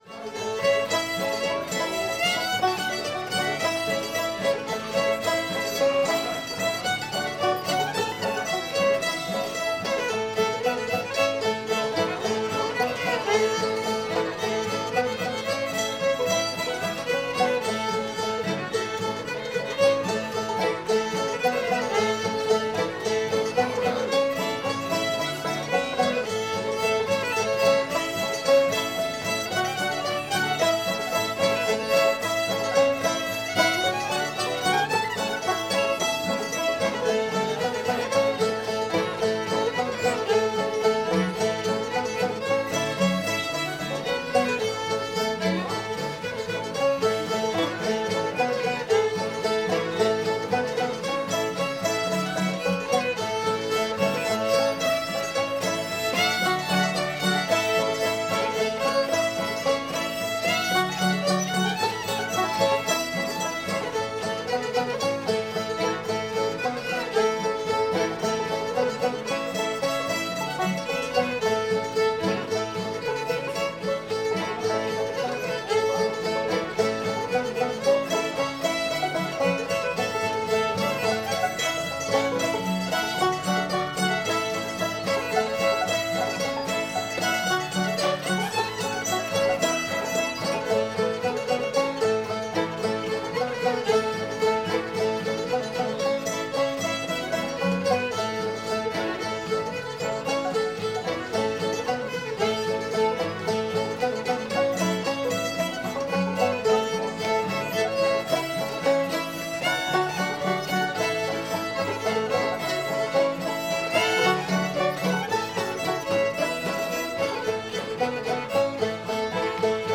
railroad runs through georgia [A]